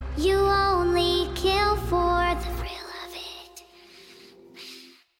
(Audio) Evil whispering